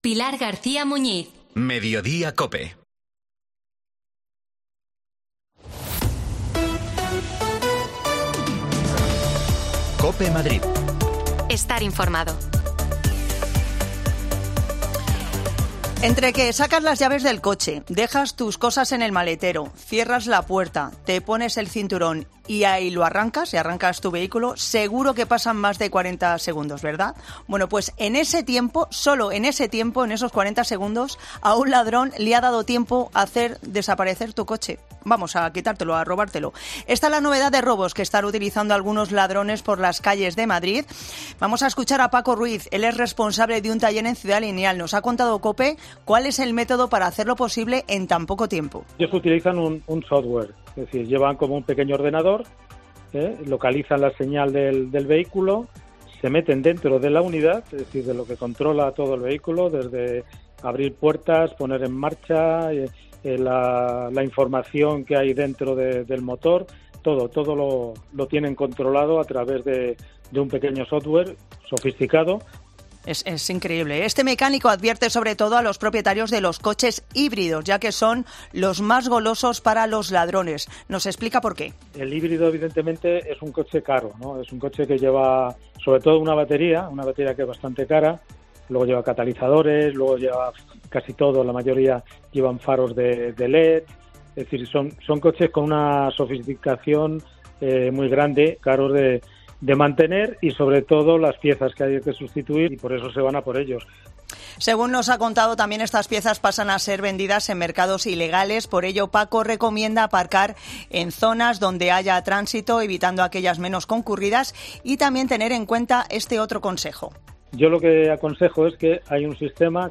AUDIO: Desmantelada una banda en Madrid que robaba coches en 40 segundos. Hablamos con un experto